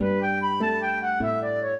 flute-harp
minuet14-2.wav